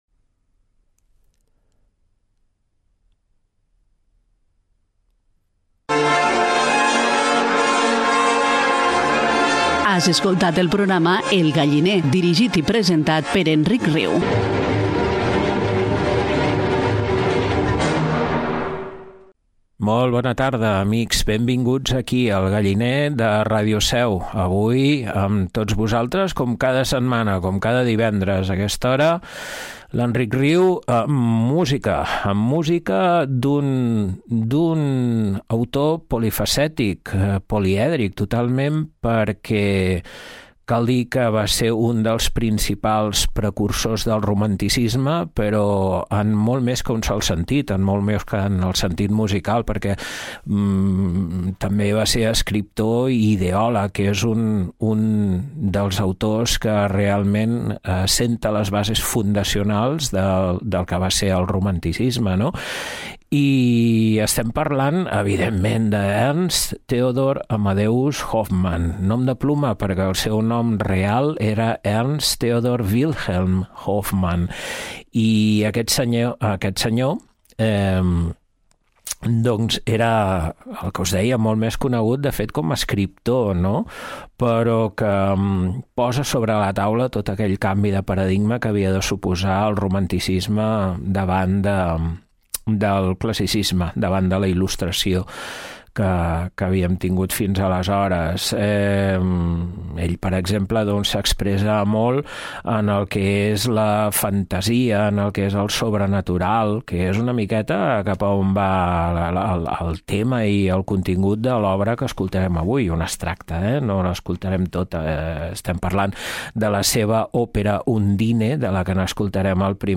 Programa de música clàssica